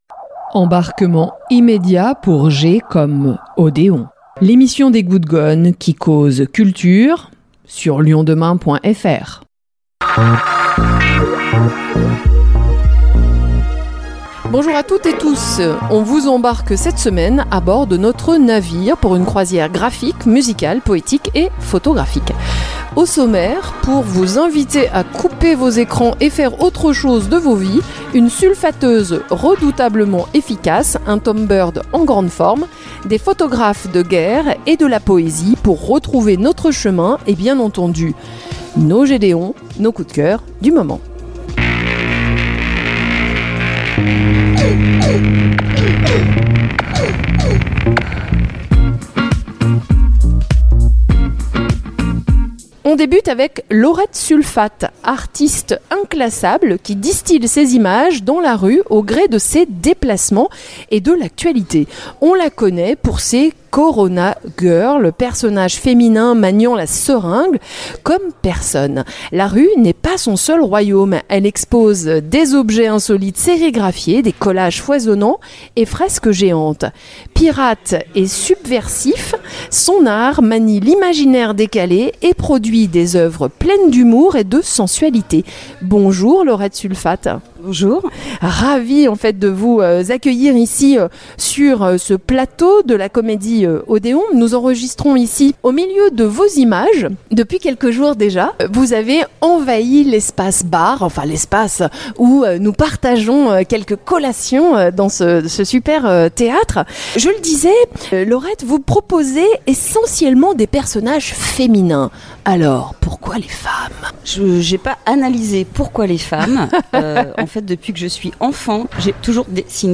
De l’humour, de la tendresse, des sonorités folk et des envolées vocales ; de la chanson française qui parle à chacun.